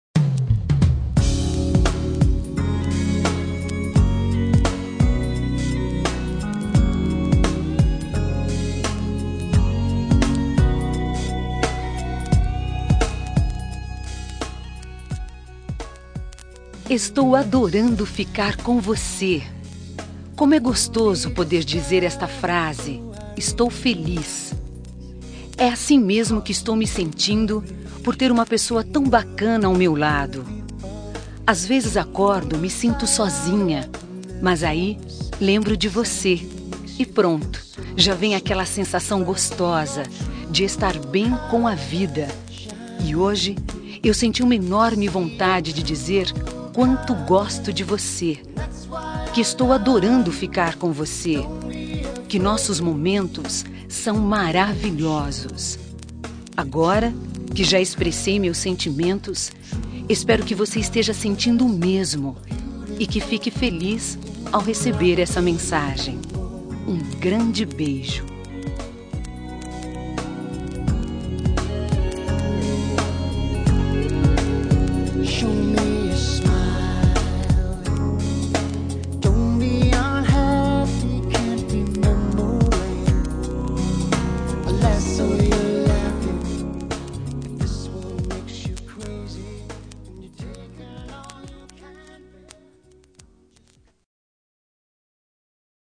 Telemensagem Início de Namoro – Voz Feminina – Cód: 746 – Adorando ficar com Você